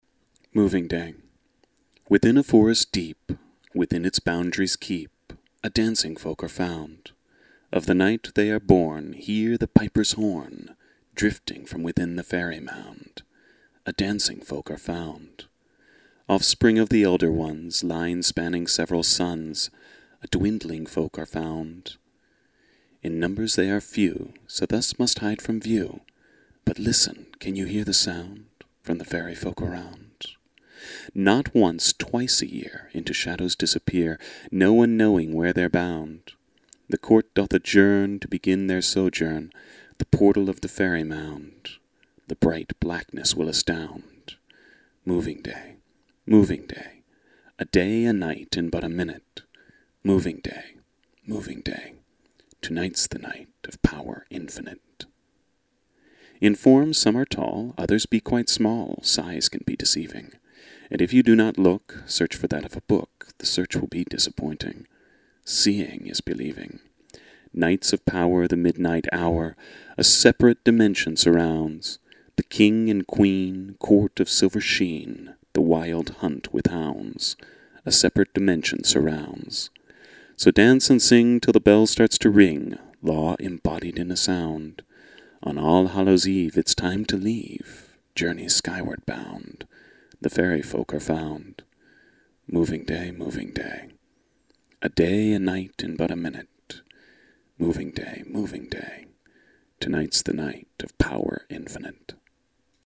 Poor quality recordings...